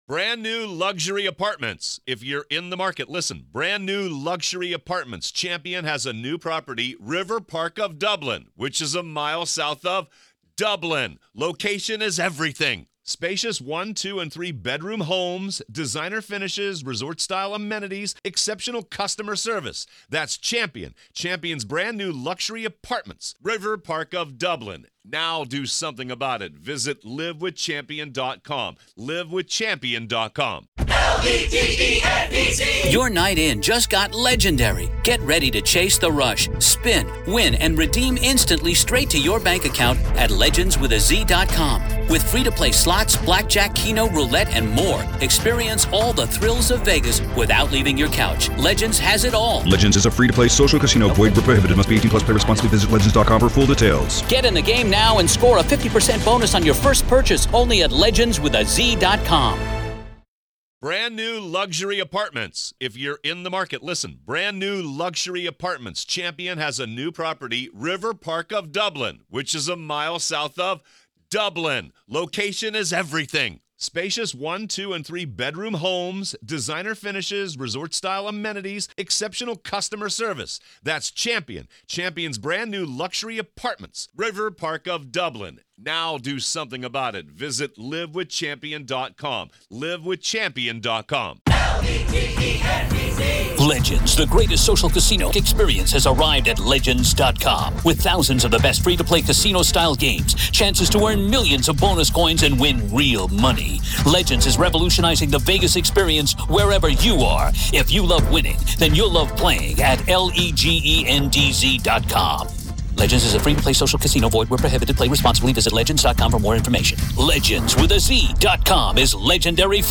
a concerning pattern emerged during a conversation with Trial Consultant and Body Language Expert